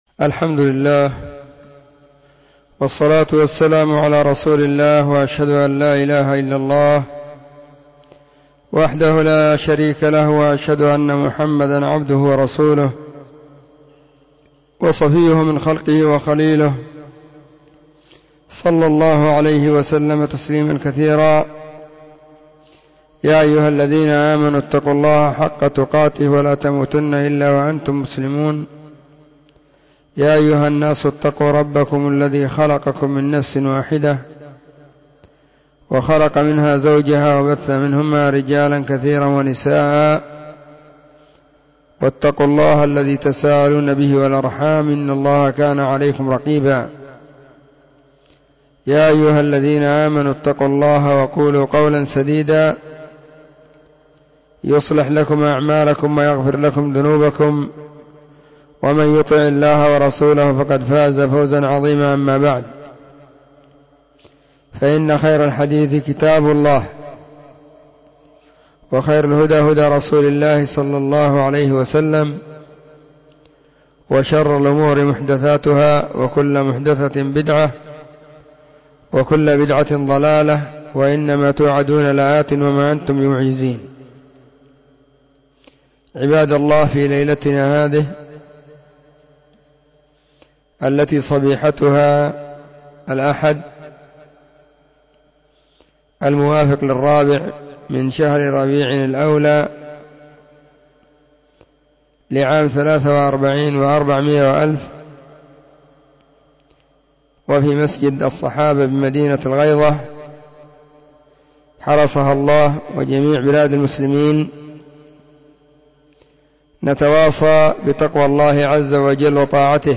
محاضرة بعنوان *((فتح البر في نصح الطلاب قبل السفر))*
📢 مسجد الصحابة – بالغيضة – المهرة، اليمن حرسها الله،